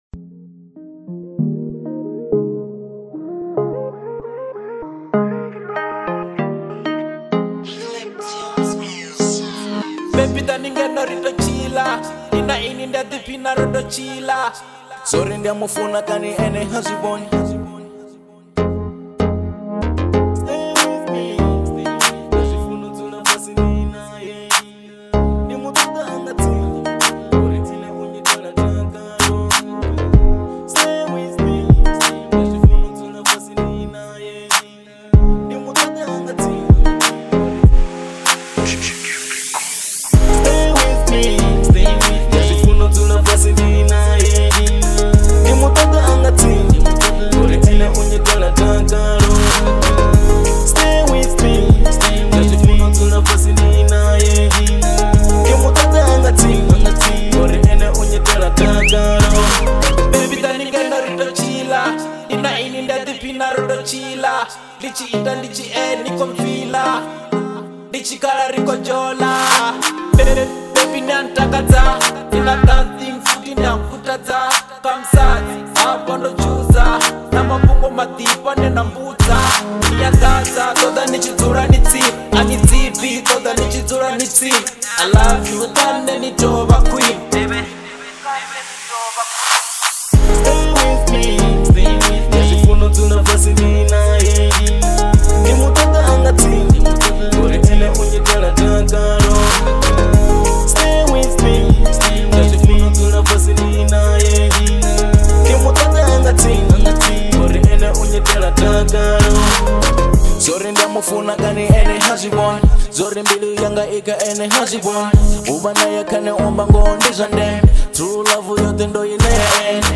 03:02 Genre : Venrap Size